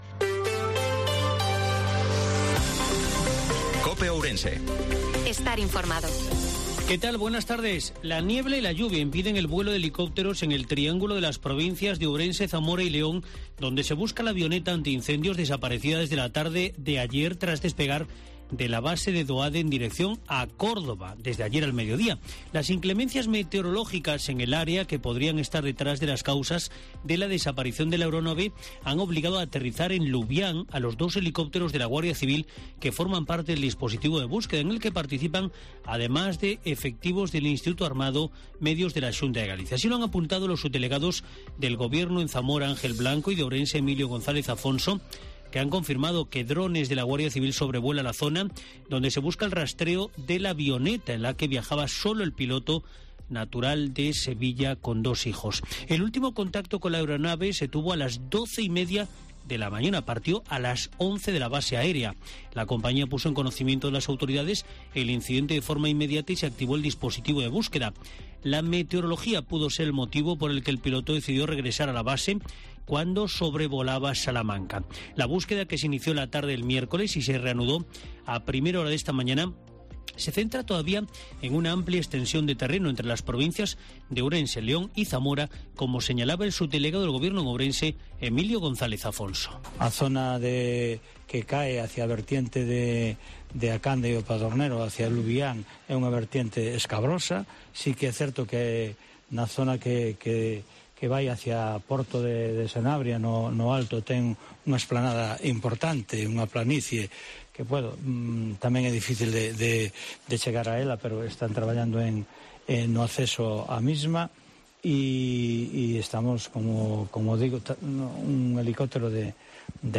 INFORMATIVO MEDIODIA COPE OURENSE-20/10/2022